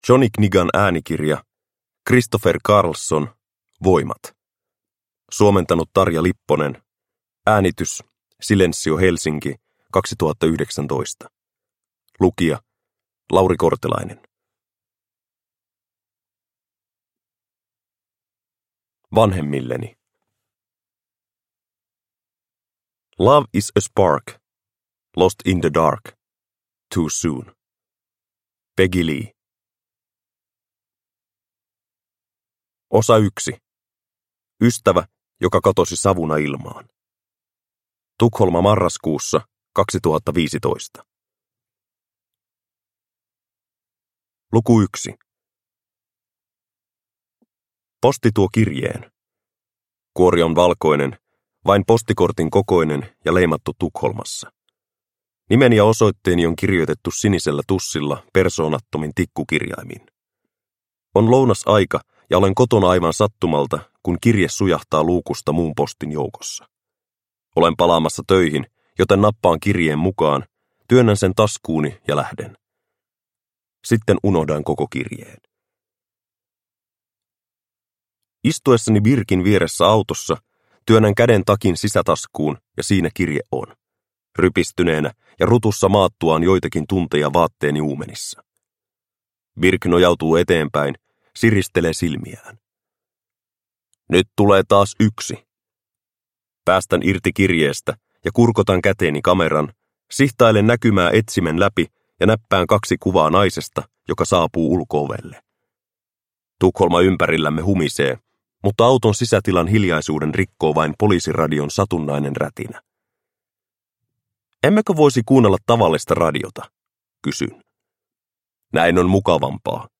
Voimat – Ljudbok